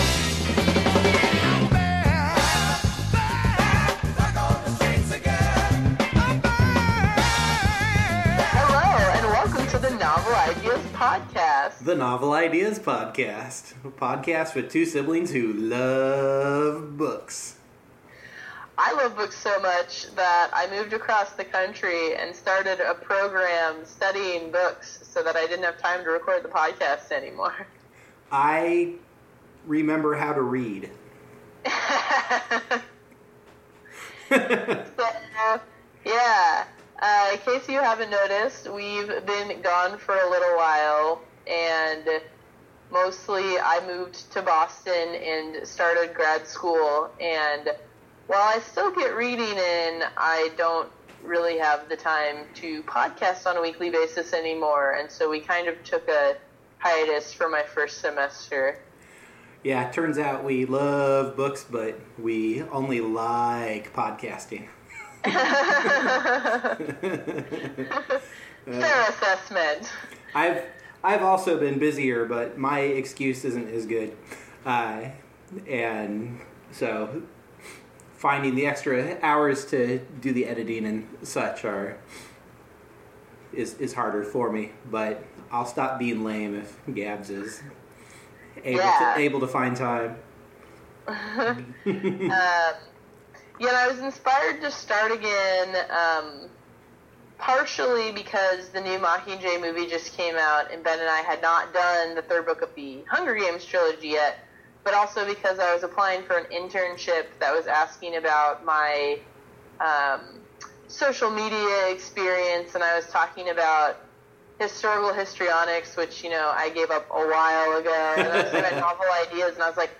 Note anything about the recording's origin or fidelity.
If the audio quality seems off, we recorded and mixed this on a new rig.